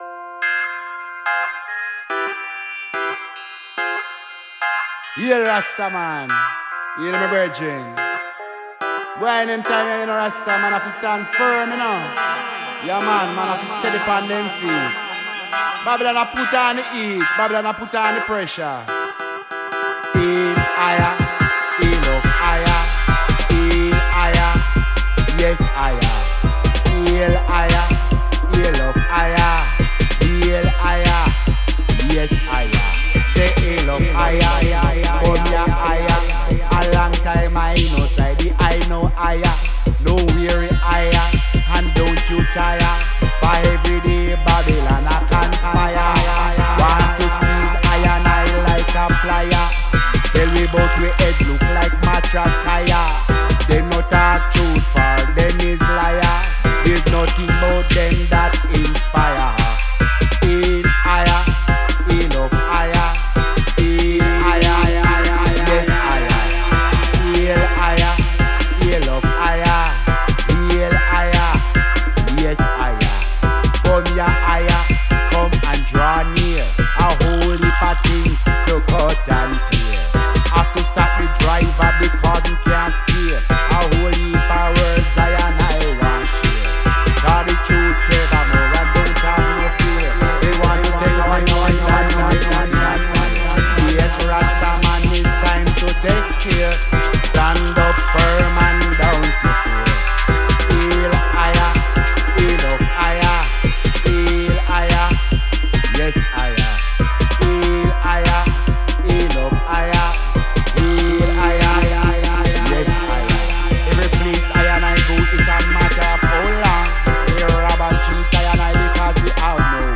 Voice Recorded at Conscious Sounds Studio London UK